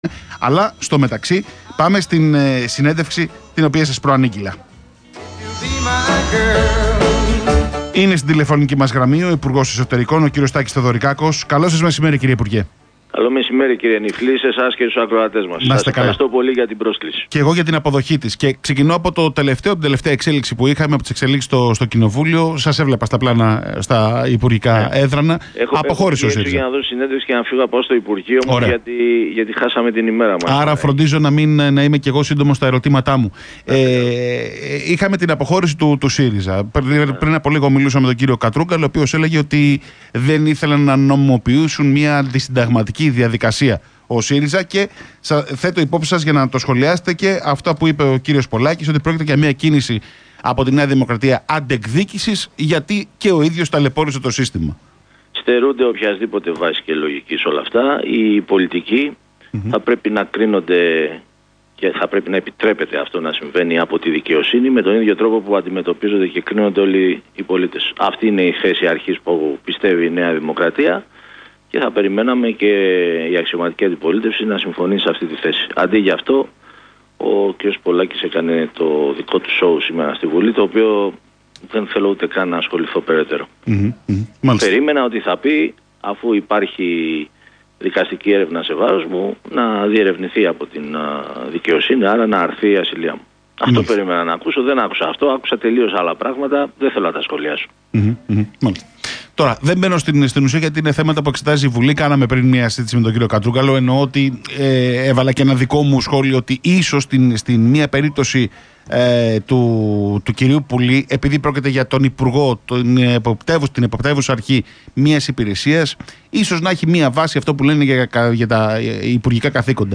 Συνέντευξη Τ. Θεοδωρικάκου στον Real Fm
Συνέντευξη παραχώρησε ο Υπουργός Εσωτερικών, Τάκης Θεοδωρικάκος, την Τετάρτη 31 Ιουλίου 2019, στον Real Fm 97,8 (Ηχητικό).